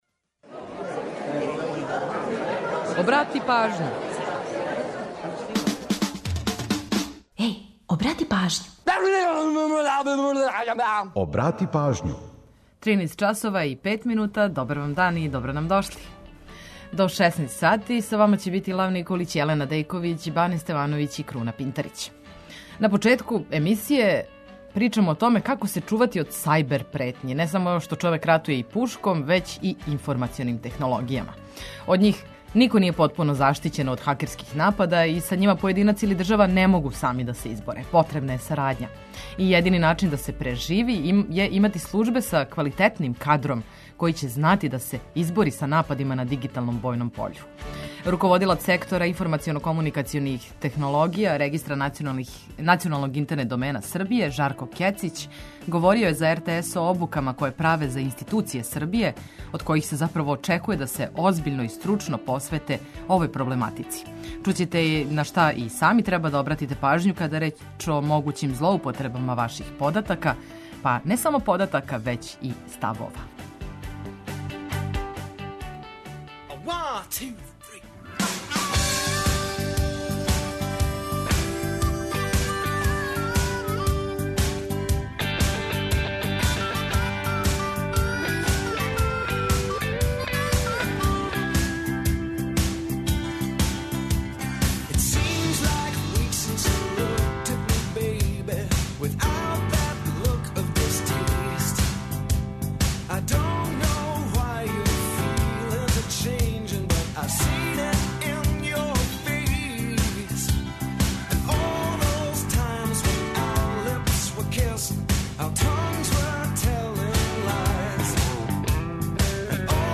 У наставку емисије предлажемо културне догађаје, свирке и концерте које можете посетити ових дана. Слушаћемо песме неколико музичара рођених на данашњи дан и албума који обележавају годишњицу објављивања. Ту је и пола сата песама само из Србије и региона, новитети са топ листа, приче о песмама и наш репортер са актуелним градским информацијама.